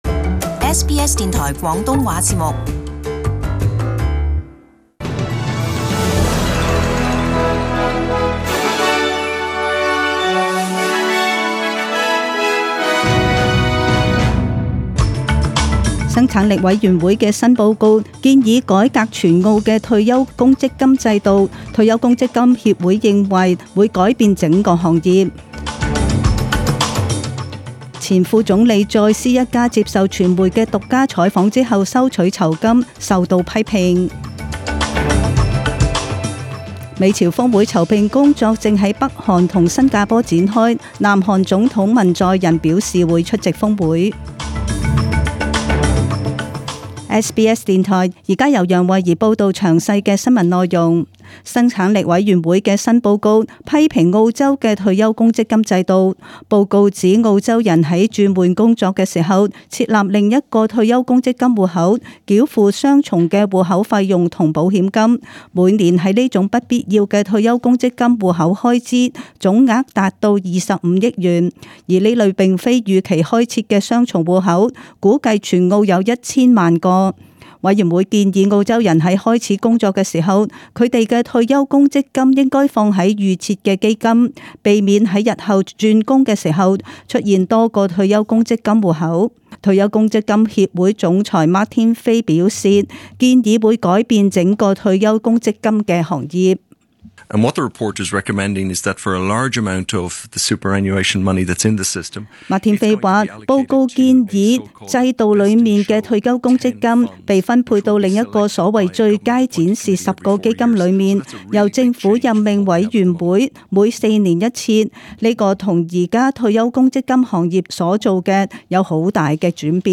SBS中文新聞 （五月廿九日）